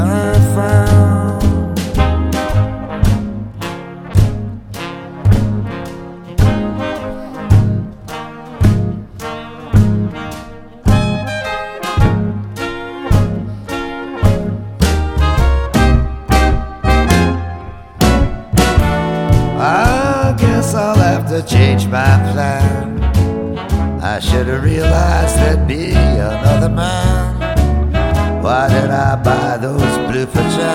"enPreferredTerm" => "Folk rock, country rock, blues rock"